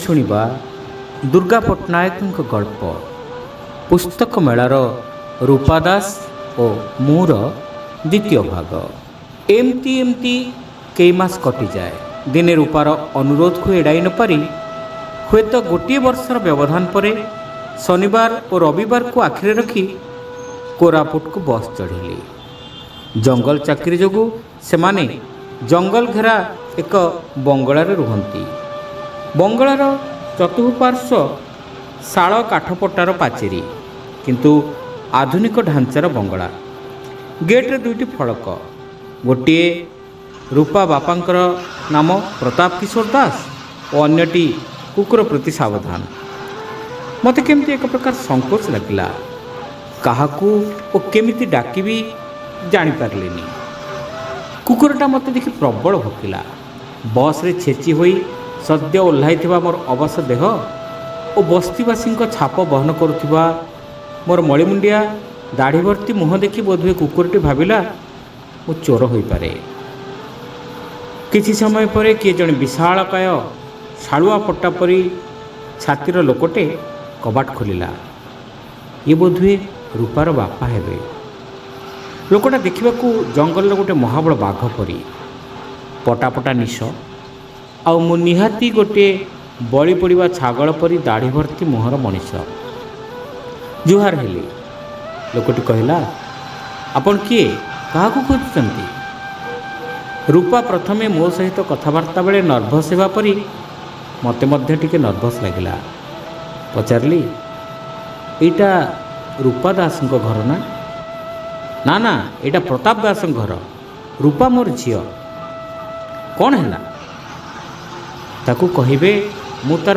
ଶ୍ରାବ୍ୟ ଗଳ୍ପ : ପୁସ୍ତକ ମେଳାର ରୁପା ଦାସ ଓ ମୁଁ (ଦ୍ୱିତୀୟ ଭାଗ)